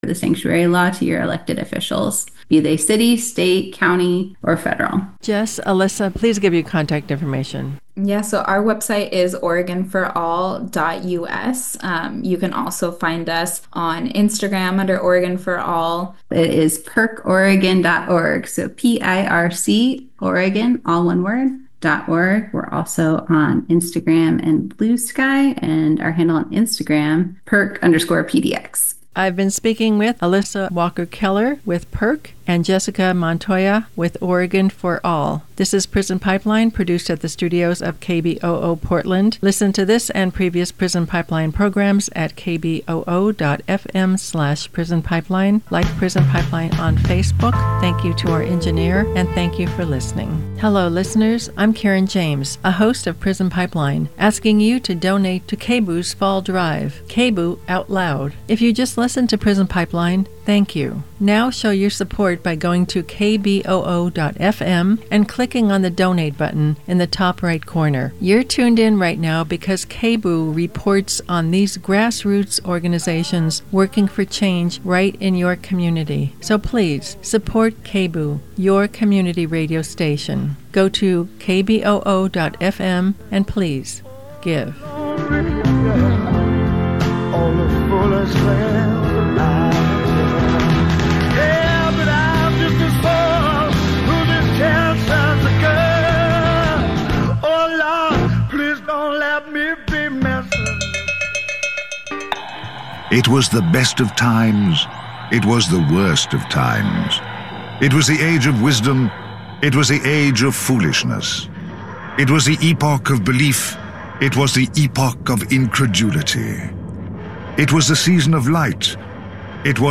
Daily Hip Hop Talk Show